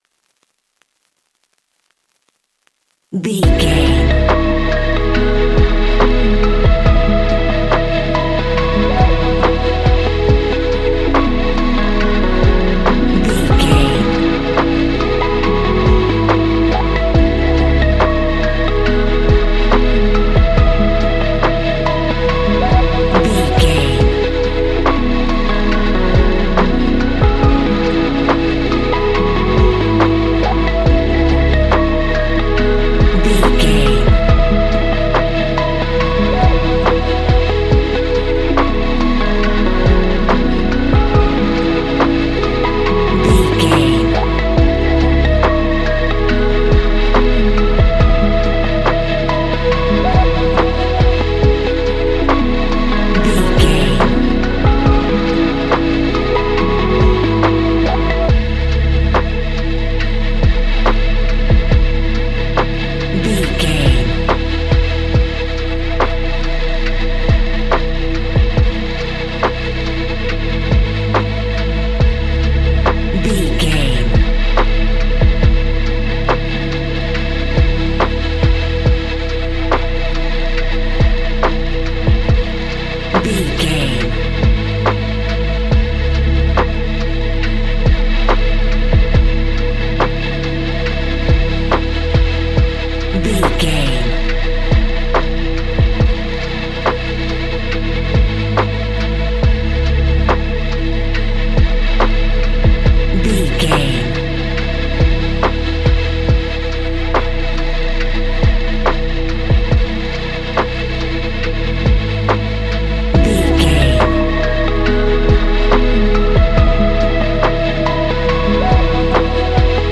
Ionian/Major
C♯
chilled
laid back
Lounge
sparse
new age
chilled electronica
ambient
atmospheric
instrumentals